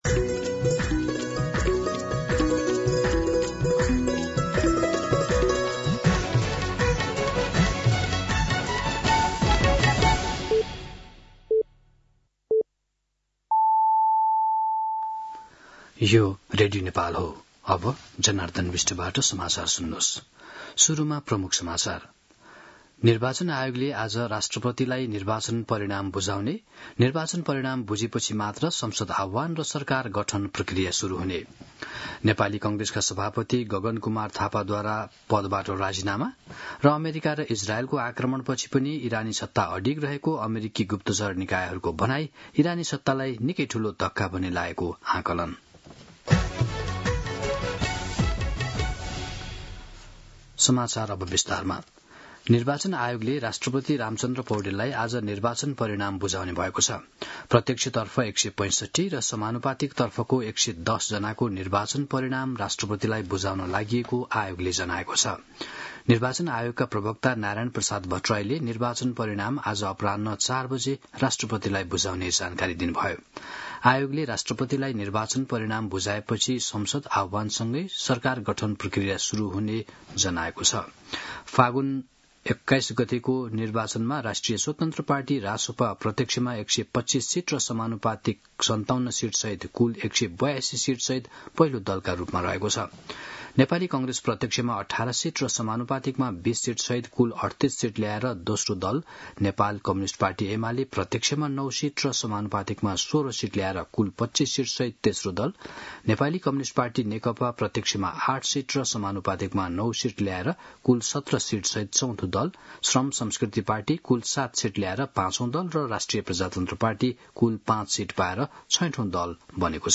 दिउँसो ३ बजेको नेपाली समाचार : ५ चैत , २०८२